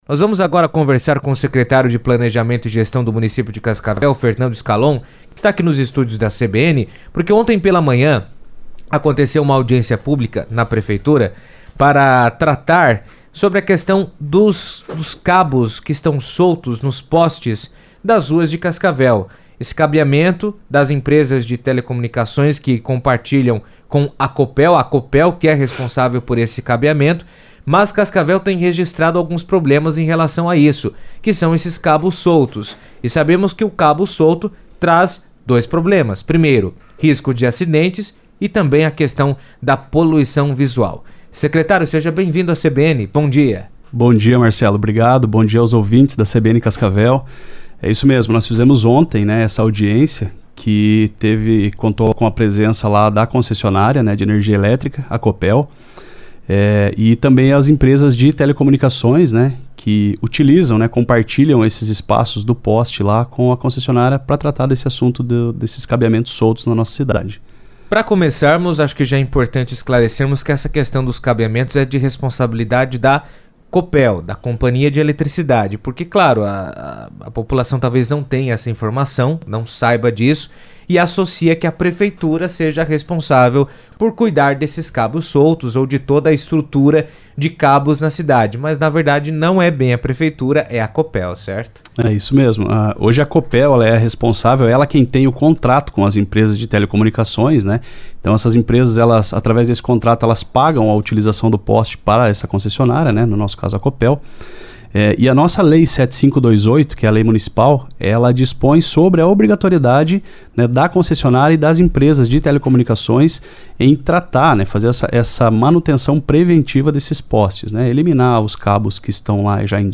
Fernando Scalon, Secretário de Planejamento e Gestão, apresentou durante entrevista na CBN, os encaminhamentos decididos a partir da audiência pública realizada na manhã da última segunda-feira (3).